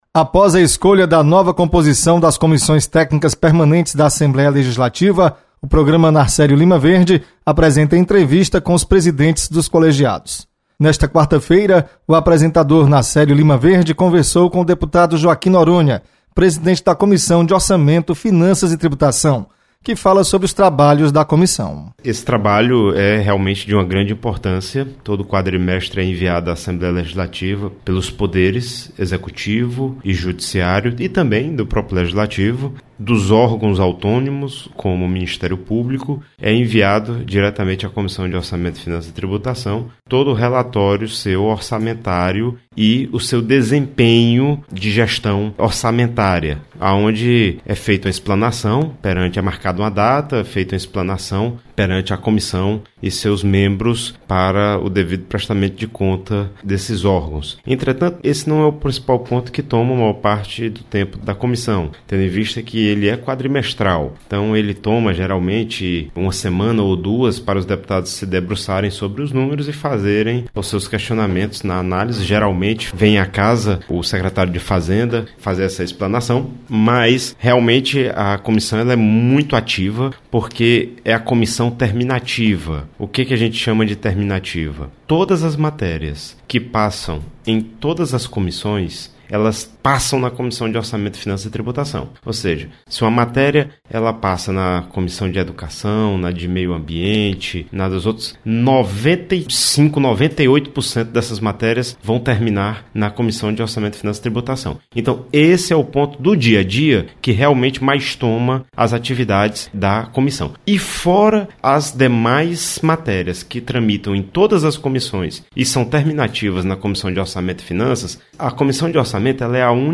Deputado Joaquim Noronha apresenta demandas da Comissão de Orçamento, Finanças e Tributação.